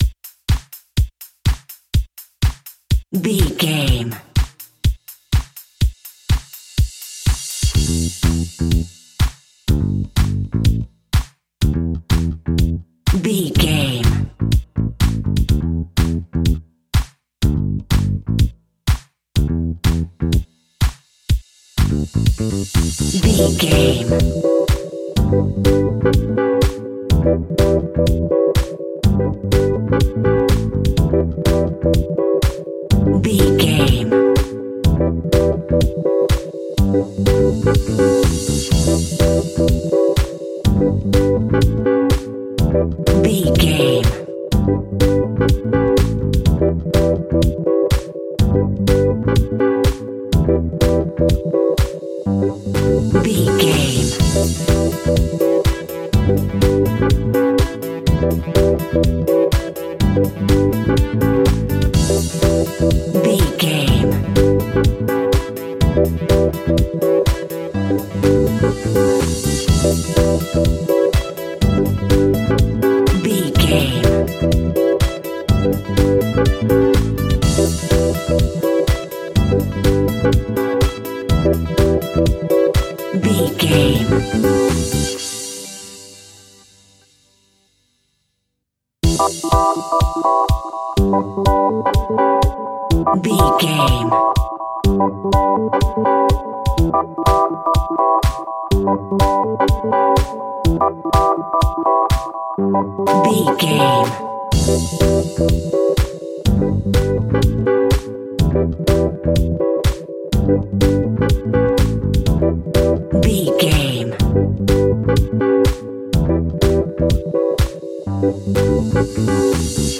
Aeolian/Minor
groovy
uplifting
driving
energetic
funky
bass guitar
drums
electric piano
synthesiser
funky house
post disco
nu disco
upbeat
instrumentals
wah clavinet
horns